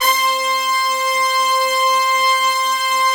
C5 POP BRASS.wav